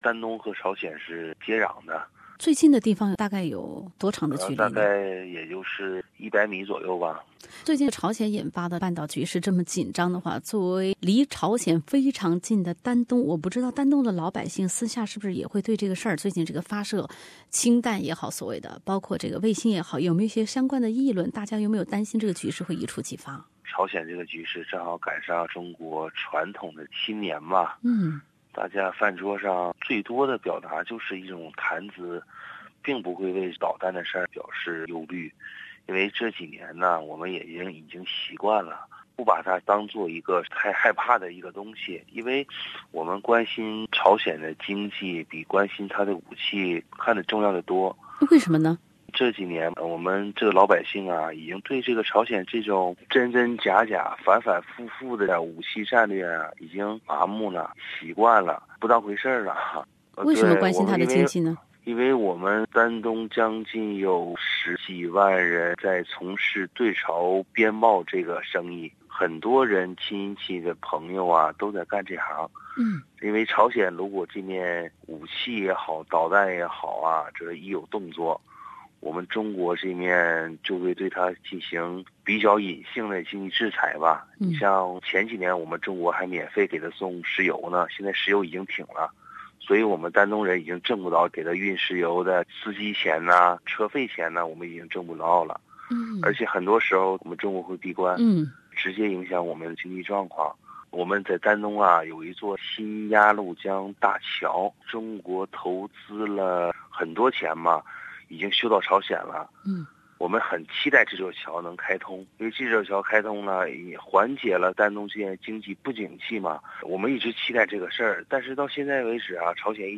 一位丹东市民的自述： 我所看到的中朝关系变化